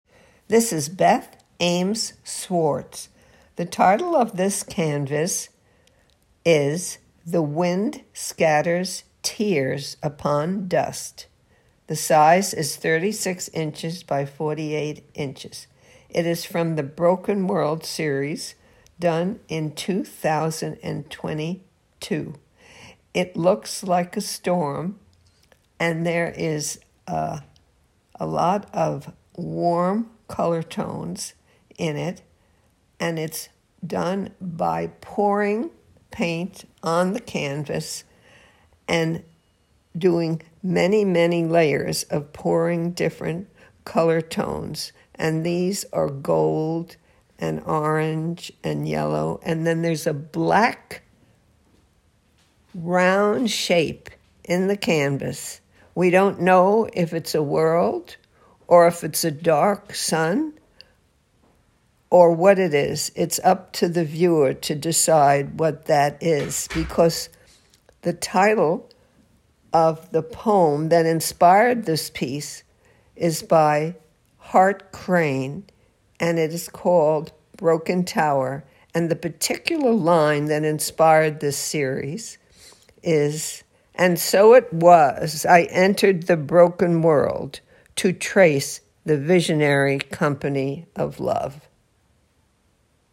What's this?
Audio Description: